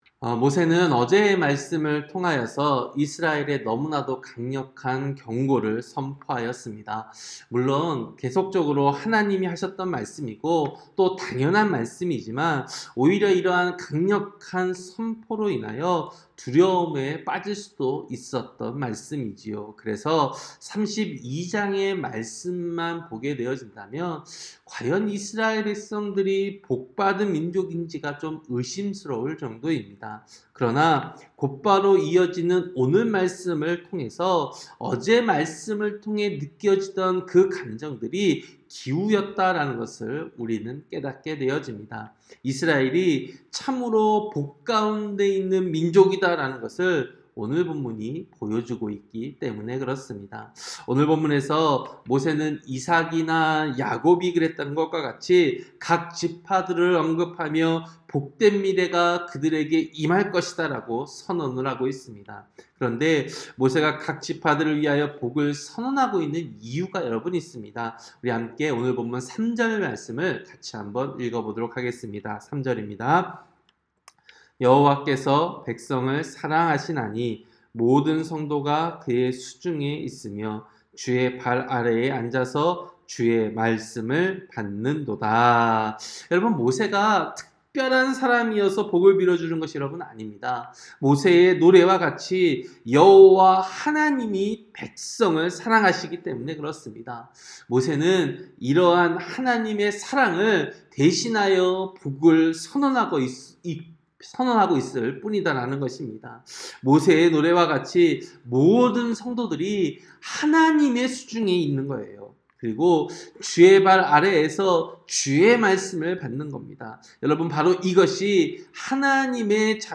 새벽설교-신명기 33장